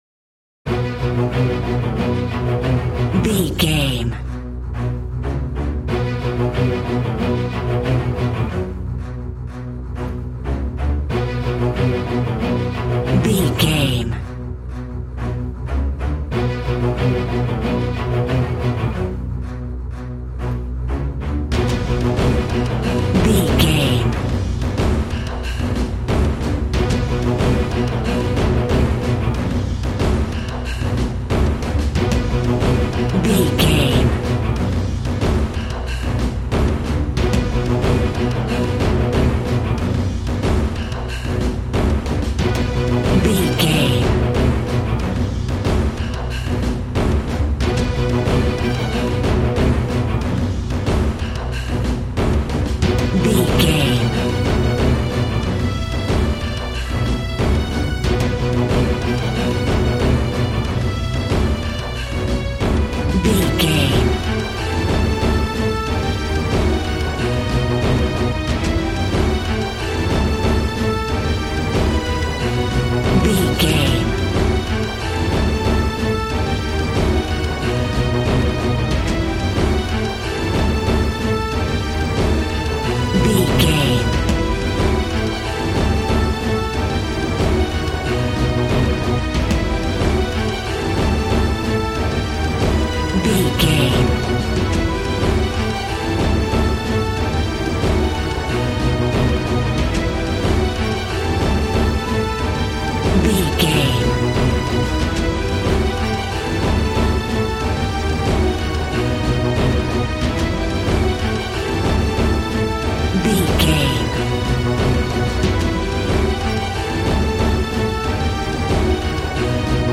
Fast paced
In-crescendo
Uplifting
Aeolian/Minor
strings
brass
percussion
synthesiser